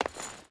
Stone Chain Run 2.ogg